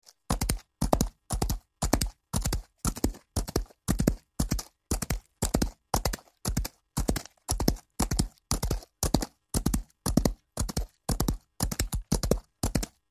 دانلود صدای اسب 11 از ساعد نیوز با لینک مستقیم و کیفیت بالا
جلوه های صوتی